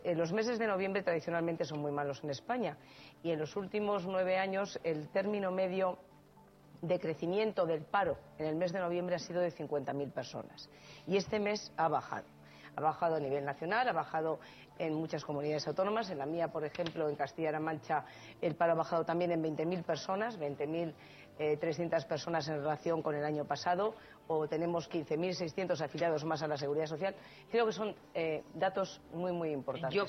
CASTILLA LA MANCHA Hoy, en El programa de Ana Rosa, tras conocerse los datos del paro correspondientes al mes de noviembre Ampliar Cospedal es entrevistada en el programa de Ana Rosa.